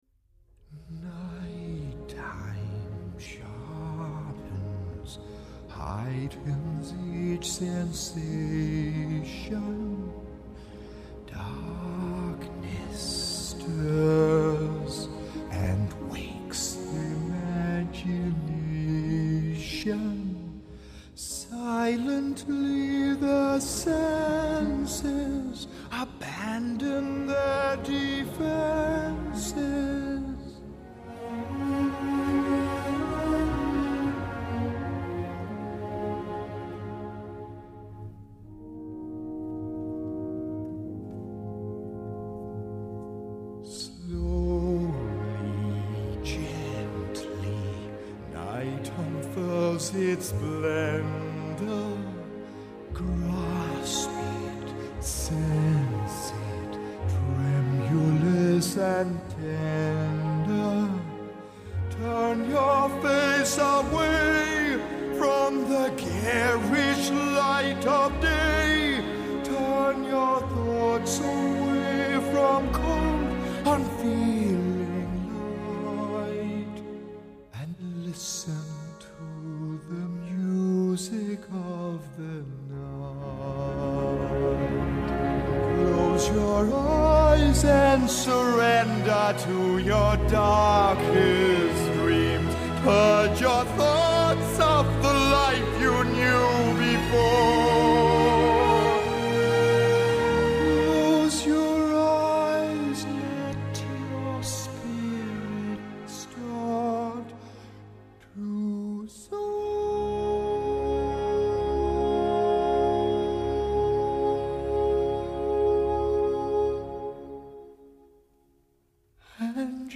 音乐类型：电影配乐
集合全好莱坞及百老汇最能歌善舞演员 亲自演唱